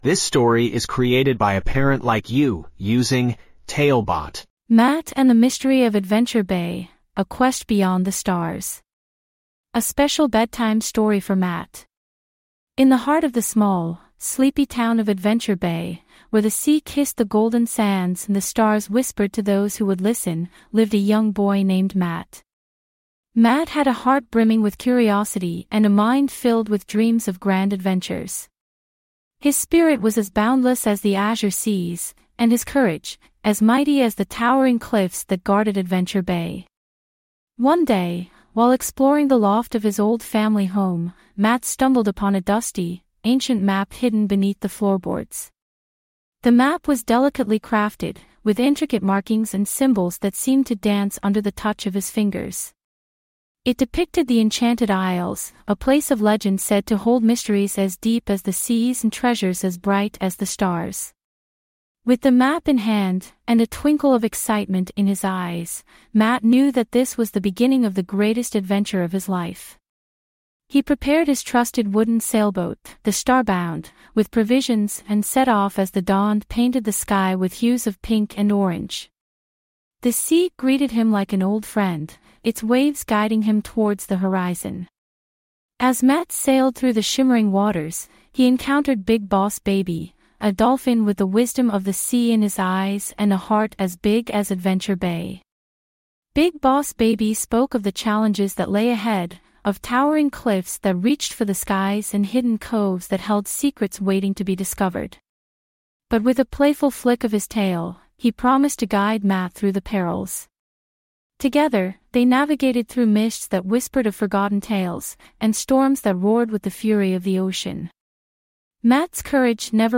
5 minute bedtime stories.